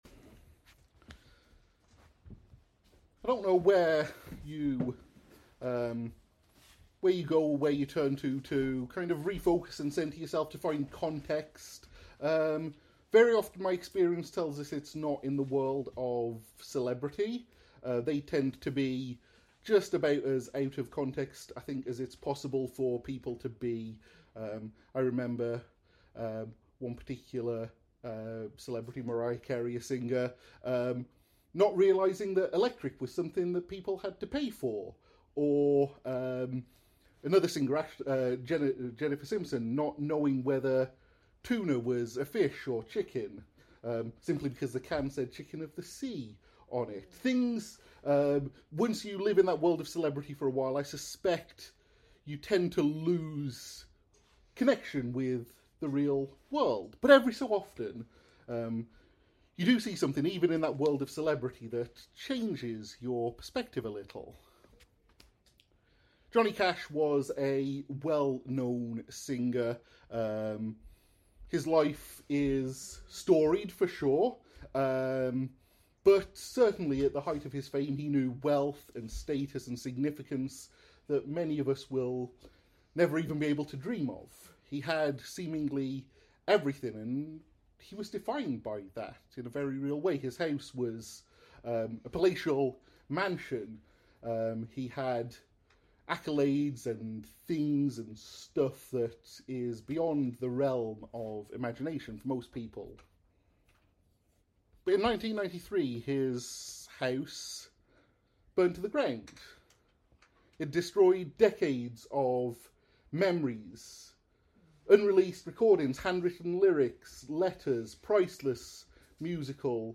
Join us for this concluding sermon in our series in Habakkuk, as we explore the kind of faith that doesn’t deny the pain, but defies despair — a joy not rooted in comfort, but in the unchanging character of God.